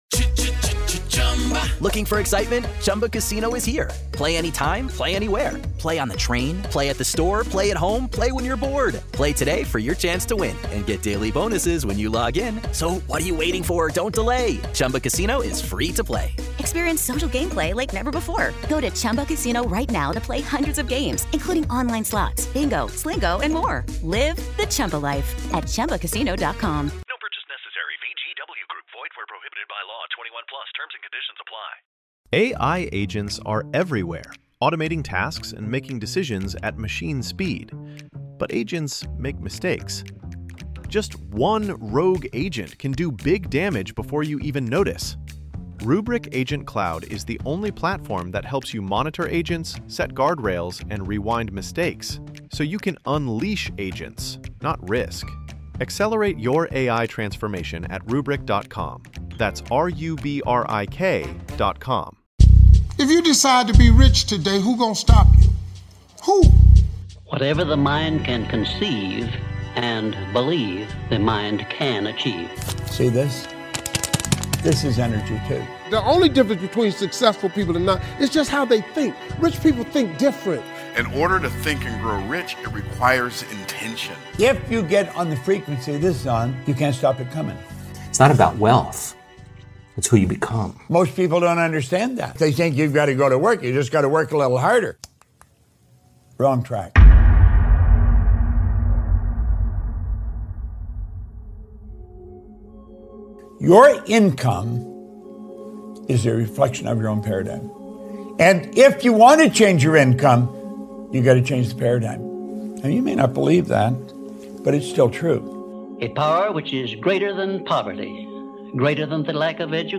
Featuring powerful speeches from thought leaders and transformative ideas, this is your ultimate guide to mastering the art of personal growth.